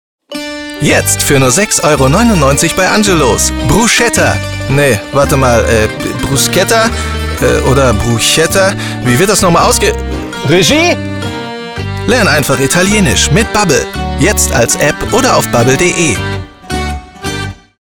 plakativ, markant, sehr variabel
Mittel minus (25-45)
Ruhrgebiet
Commercial (Werbung)